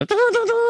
嘟嘟嘟.mp3